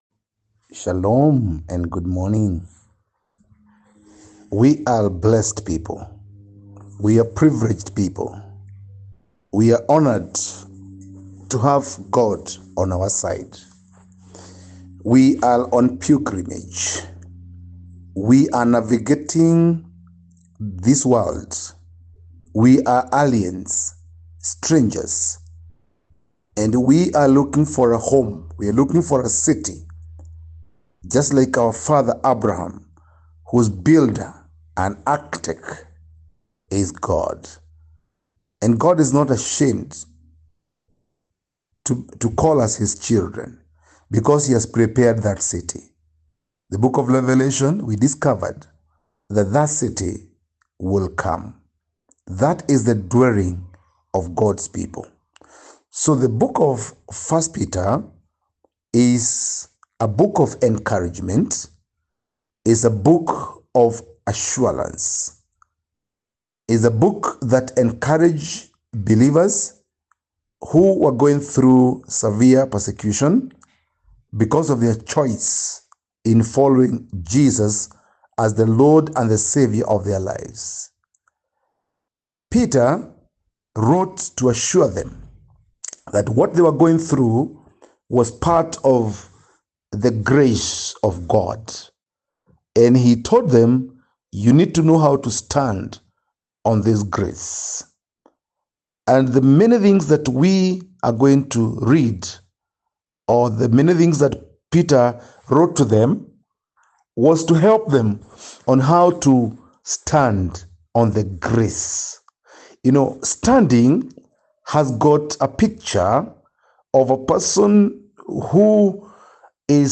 Fix your hope completely on the Grace 1 Peter 1:10-25 1-Peter-1-10-25. Audio Summary